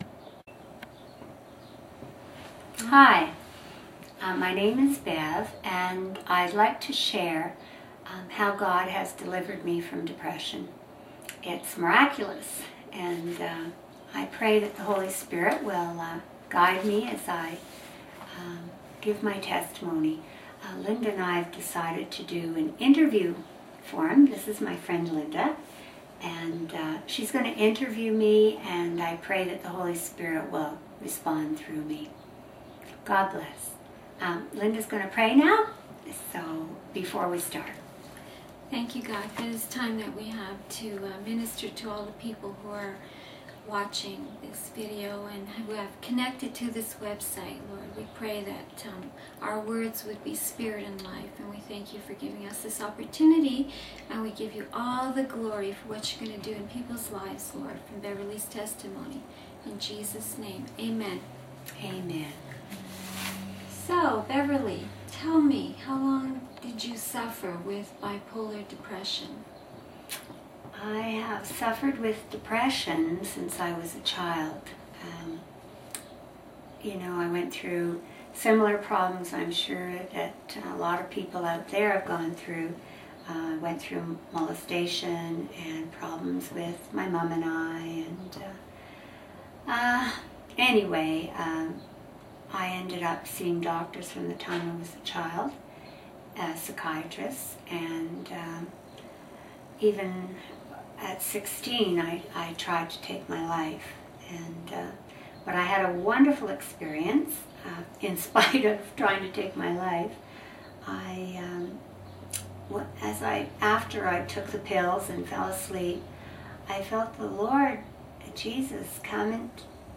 Introduction Video My Testimony Video Link to Prophetic Word Audio Version of My Testimony of Deliverance from depression Please complete this form if you think this website would minister to your friend(s).